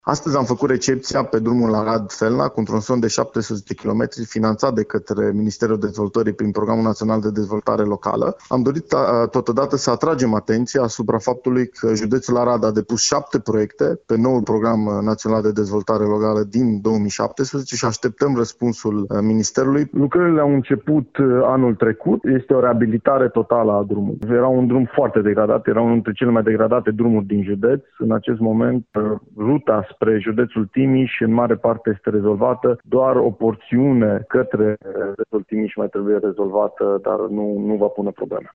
Vicepreşedintele Consiliului Judeţean Arad, Sergiu Bîlcea: